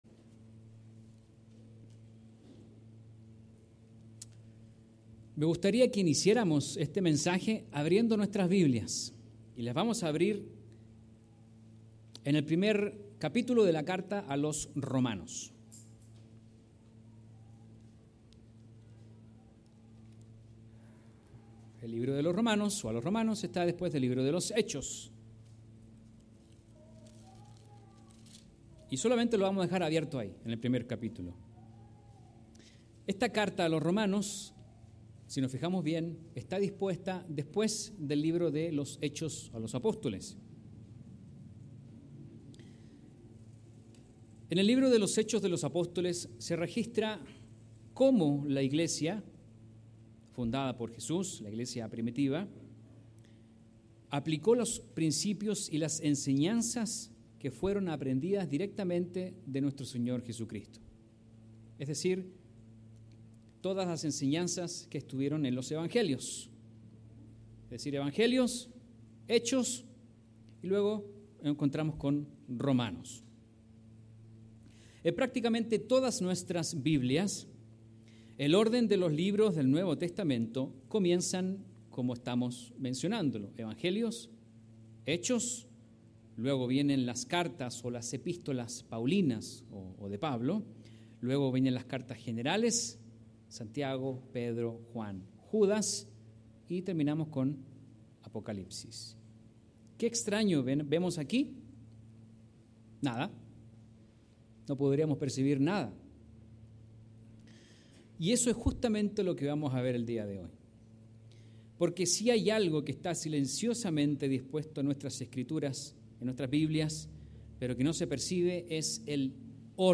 Mensaje entregado el 1 de Julio de 2023.